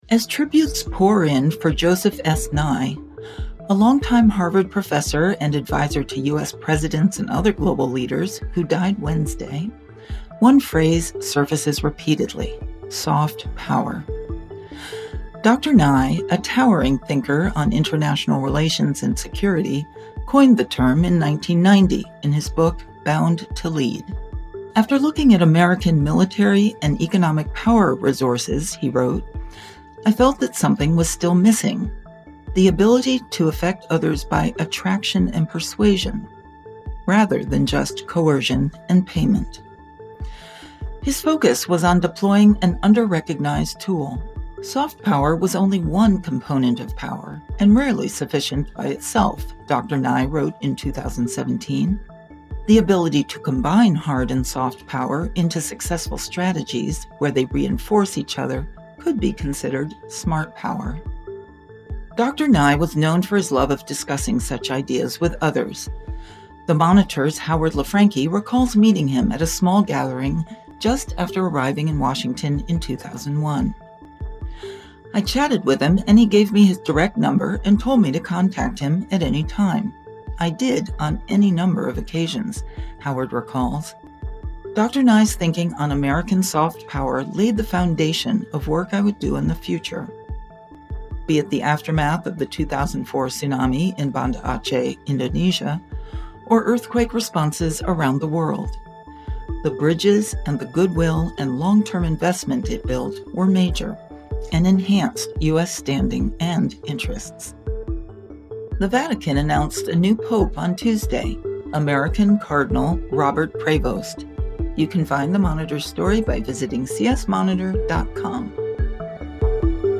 The editors of The Christian Science Monitor take you beyond the headlines with the ideas driving progress in this 15-minute news briefing.